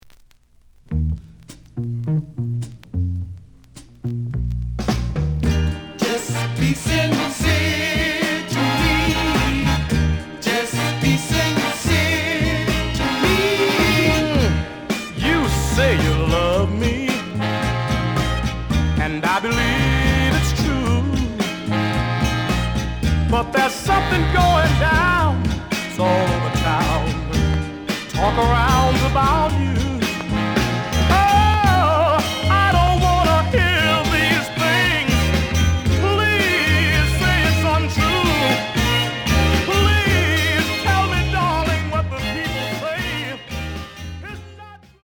The listen sample is recorded from the actual item.
●Genre: Soul, 60's Soul
●Record Grading: VG~VG+ (傷はあるが、プレイはおおむね良好。Plays good.)